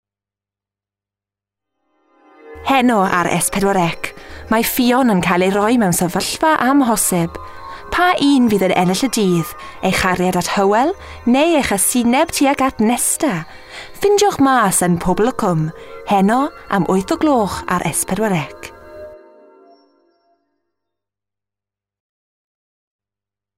Chatty, youthful Valleys tones. From Swansea to South London with a touch of Chigwell in between. Commercials & characters.